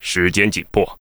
文件 文件历史 文件用途 全域文件用途 Bk_fw_01.ogg （Ogg Vorbis声音文件，长度1.0秒，132 kbps，文件大小：16 KB） 源地址:游戏语音 文件历史 点击某个日期/时间查看对应时刻的文件。